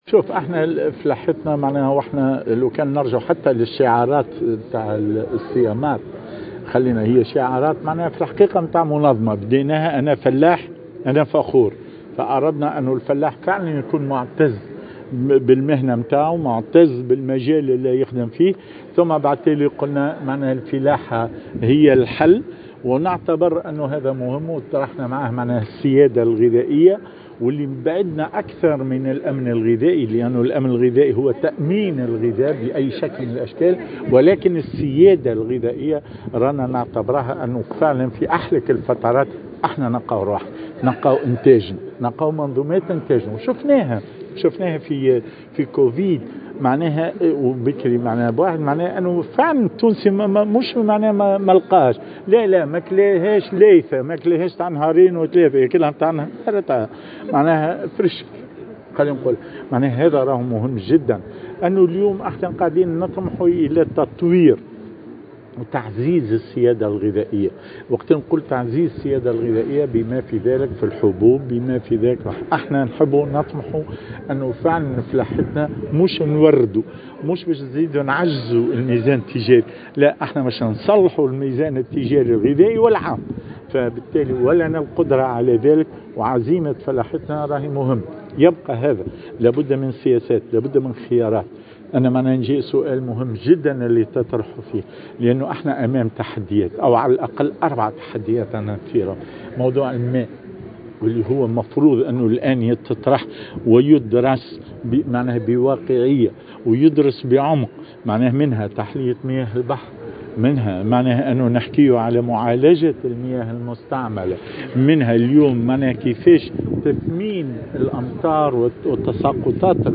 خلال ندوة صحفية حول الدورة 15 من الصالون الدولي للفلاحة والآلات الفلاحية والصيد البحري "السياماب 2021"